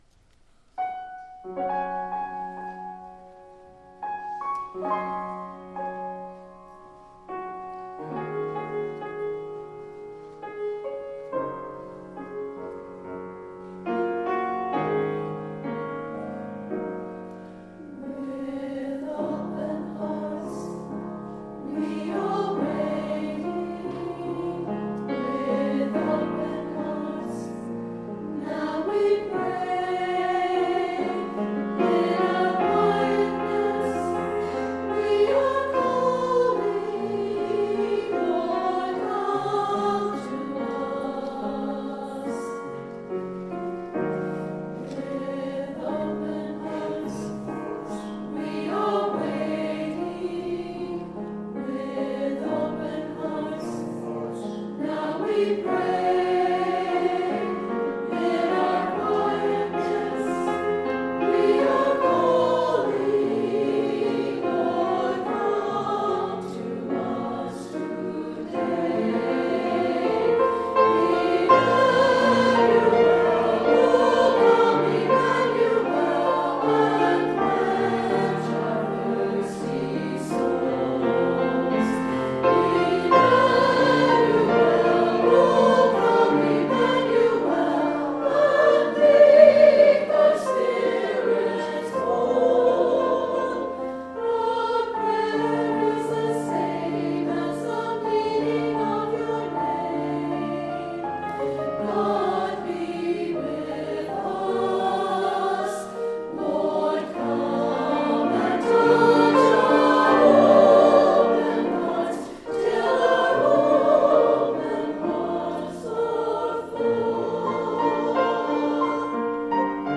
Anthem - With Open Hearts.mp3
Anthem+-+With+Open+Hearts.mp3